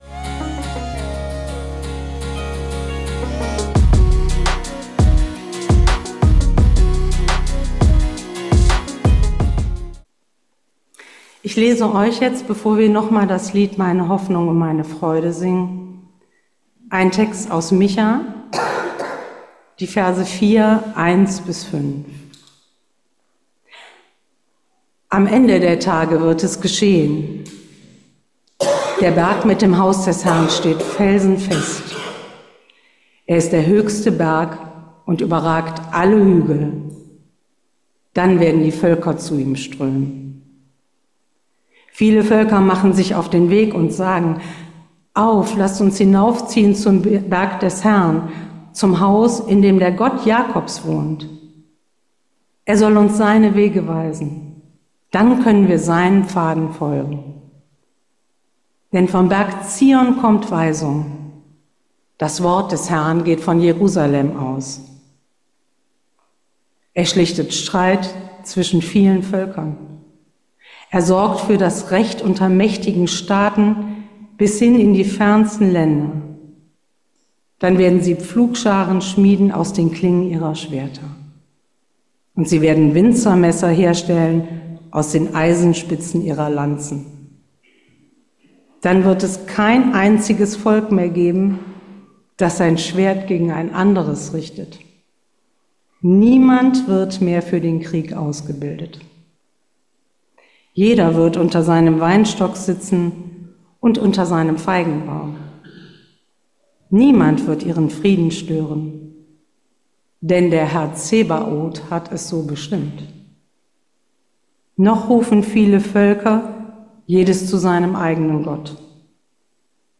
Predigt über Micha 4,1-5